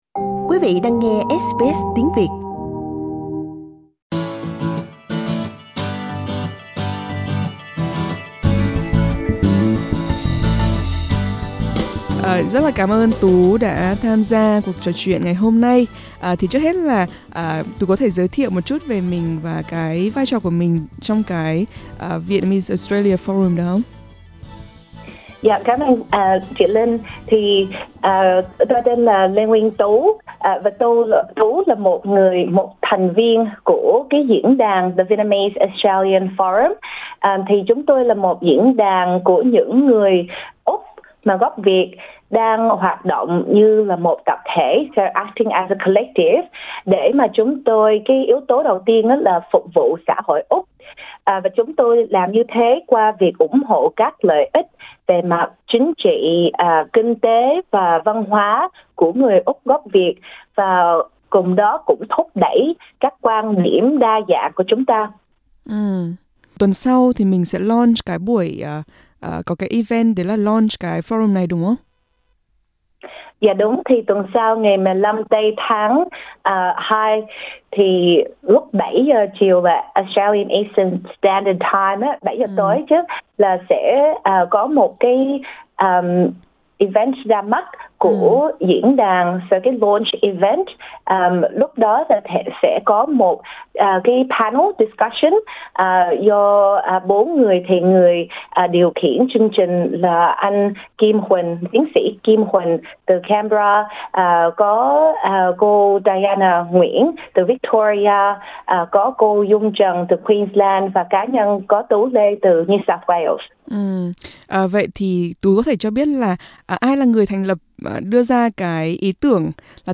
Xin mời quý vị bấm vào hình để nghe toàn bộ cuộc trò chuyện.